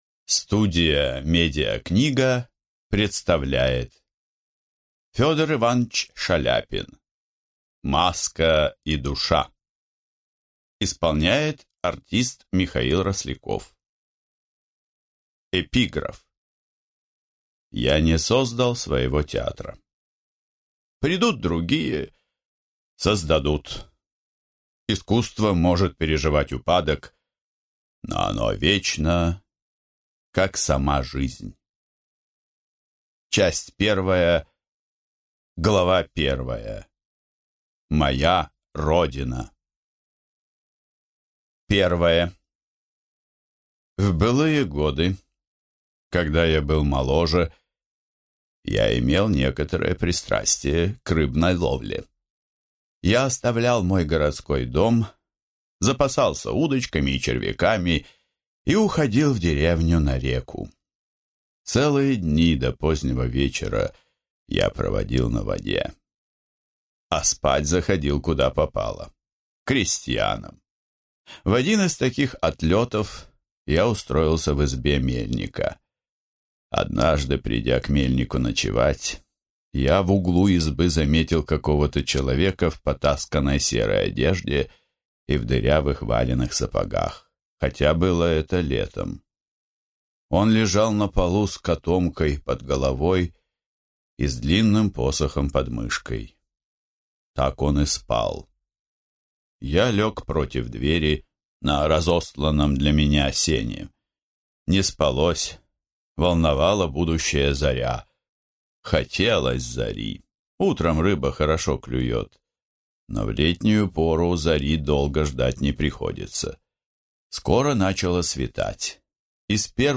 Аудиокнига Маска и душа. Страницы из моей жизни | Библиотека аудиокниг